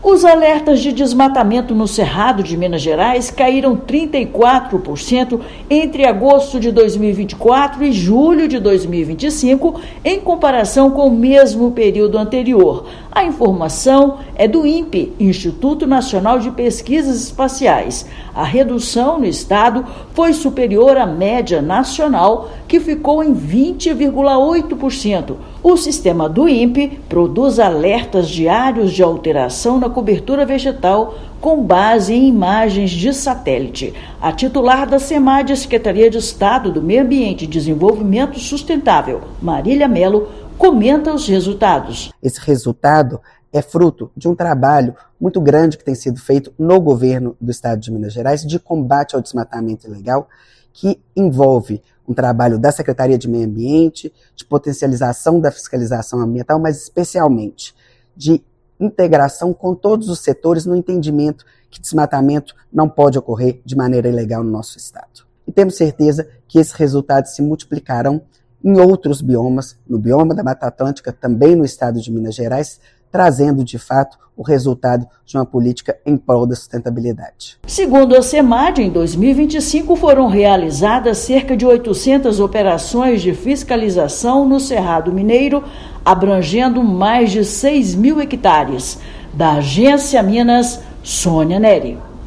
Agência Minas Gerais | [RÁDIO] Desmatamento no Cerrado de Minas Gerais cai 34% e estado supera média nacional na redução dos alertas
Ações de fiscalização e uso de tecnologia promovidos pelo Governo de Minas impulsionam resultado divulgado pelo Inpe. Ouça matéria de rádio.